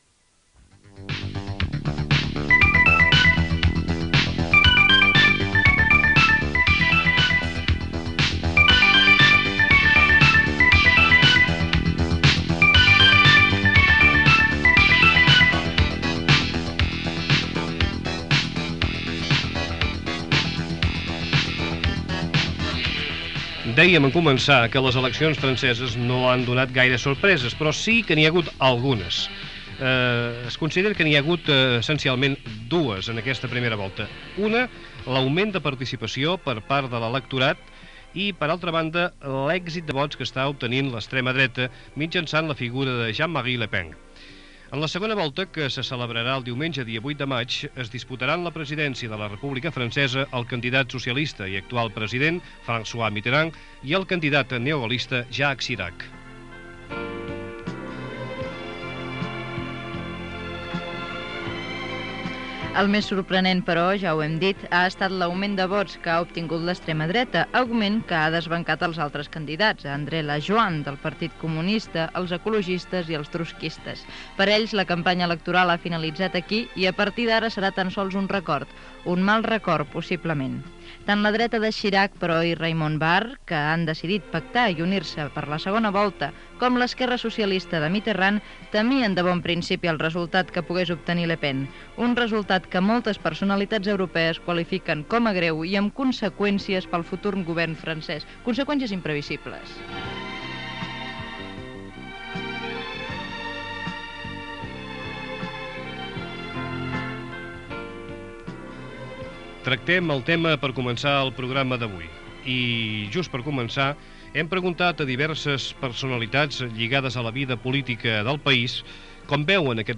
Informació des de París sobre el ressò a la premsa francesa i la situació política del país. Gènere radiofònic Info-entreteniment